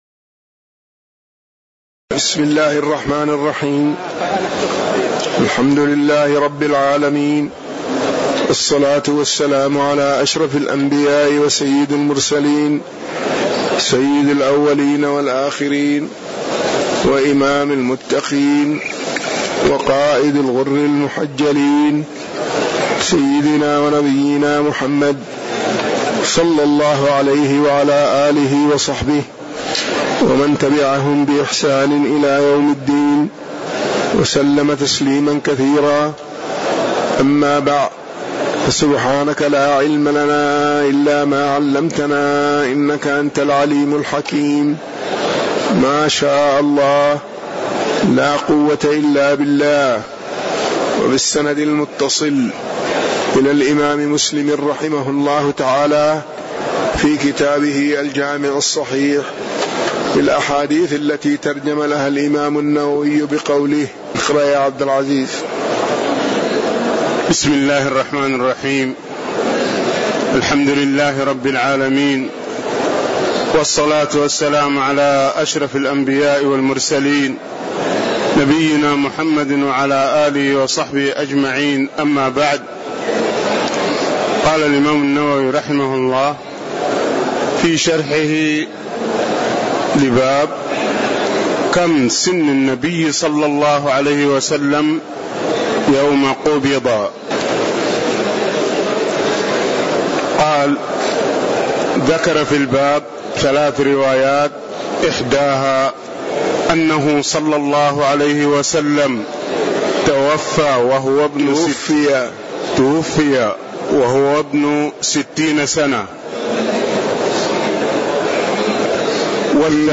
تاريخ النشر ٧ جمادى الآخرة ١٤٣٧ هـ المكان: المسجد النبوي الشيخ